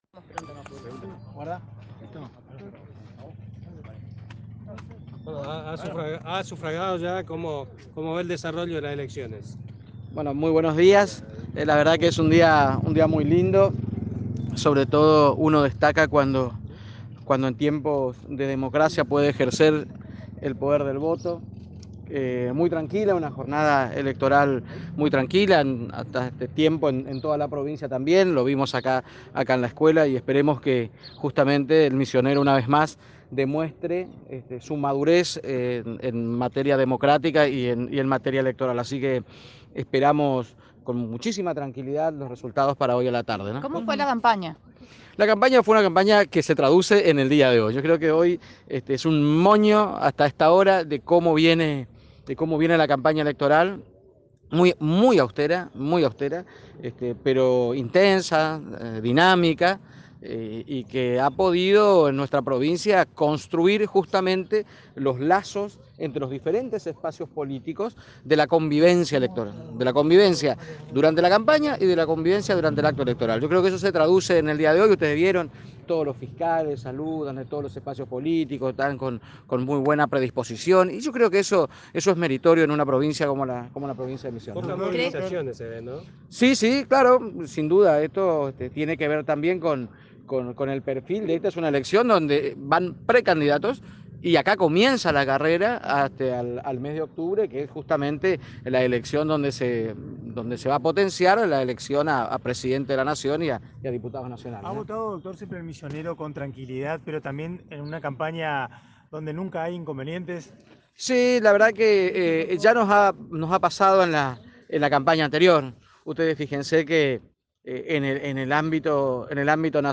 El actual vicegobernador -y gobernador electo- de Misiones, Oscar Herrera Ahuad, llegó acompañado de su hija a la Escuela 827 de la ciudad de Posadas, donde emitió su voto en el marco de las elecciones primarias, abiertas, simultáneas y obligatorias (PASO) que se desarrollan en todo el territorio argentino.